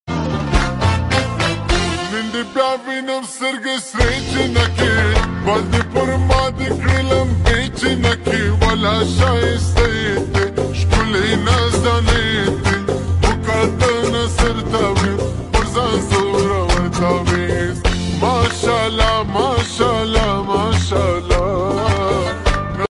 Poshto Song 😍 .